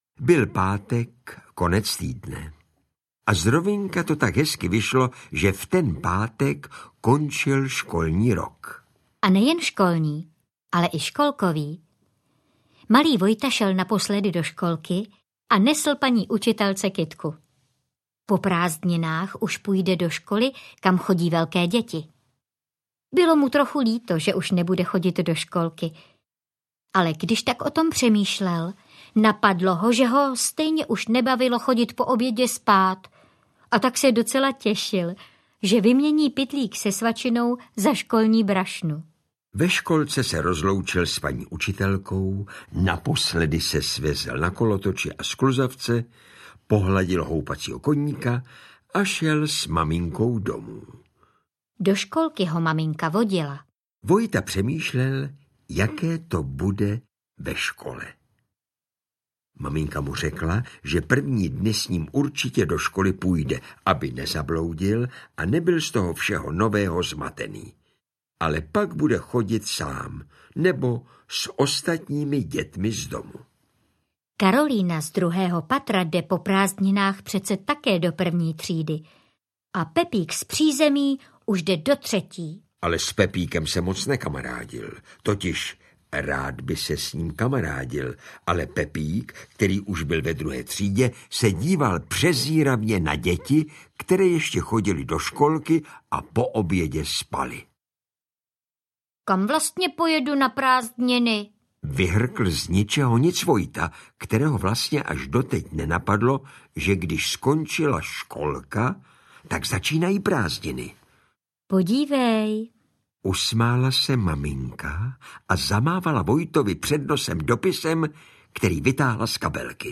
Prázdniny s dráčkem audiokniha
Ukázka z knihy
• InterpretJan Přeučil, Eva Hrušková